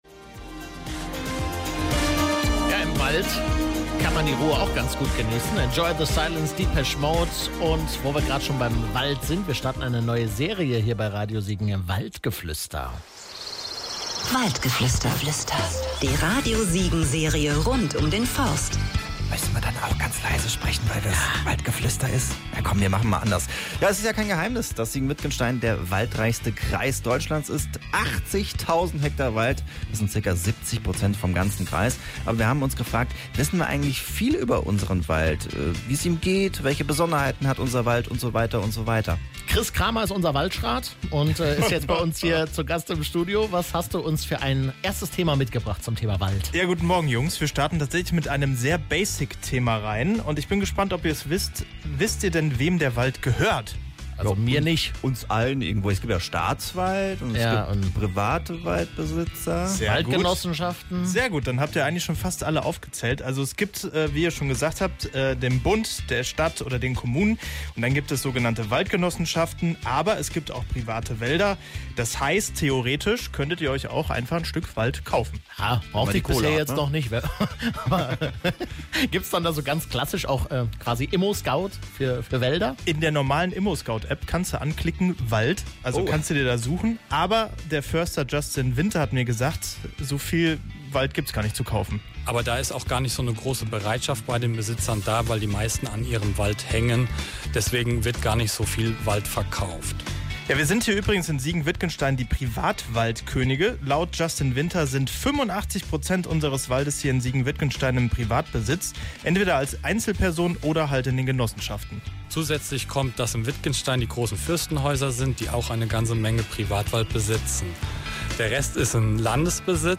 auf einen Spaziergang durch den Wald